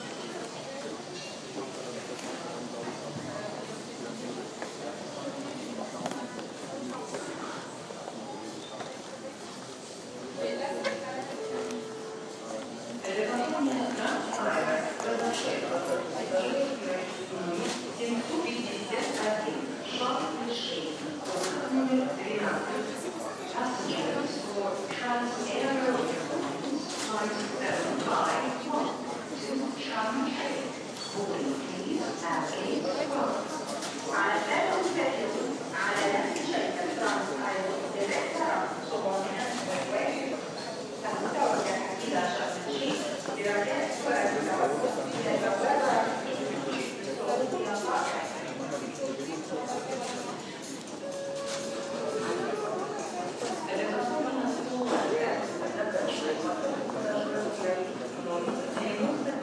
Moscow Domodedovo sound